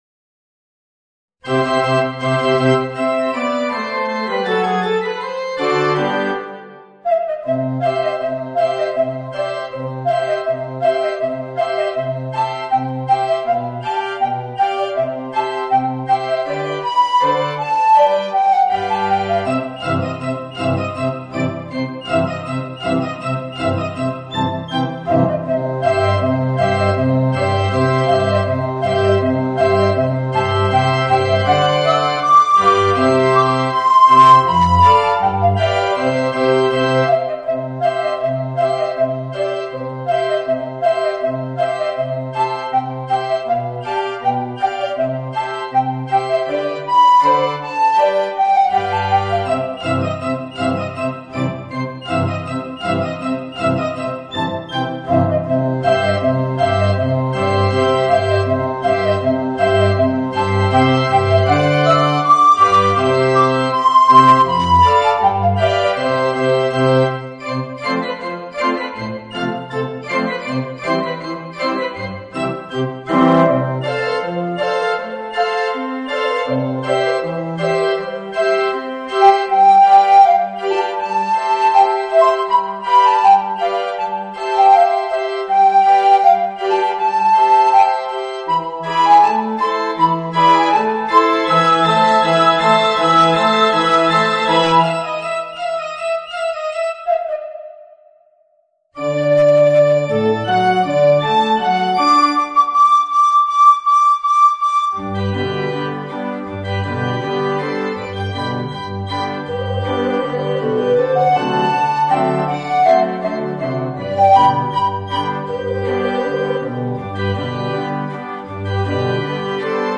Voicing: Alto Recorder and Organ